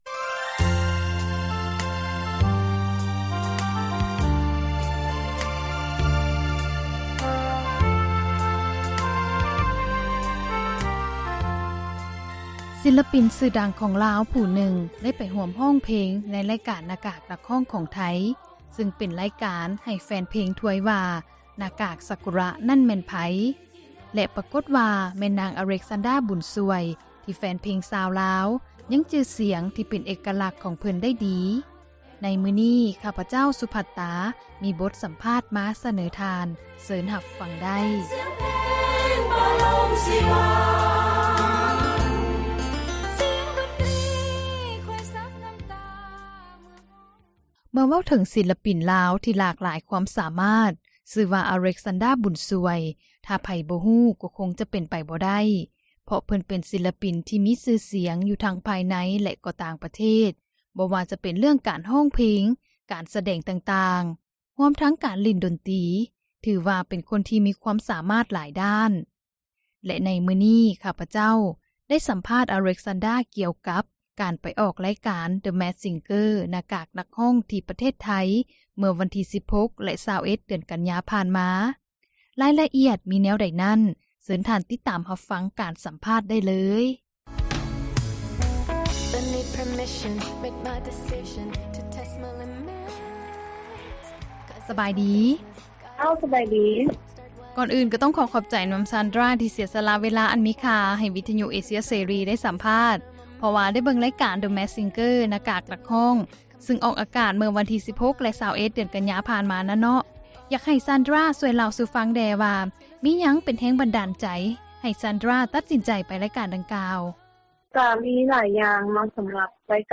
ສໍາພາດ ອາເລັກຊານດຣ້າ ບຸນຊ່ວຍ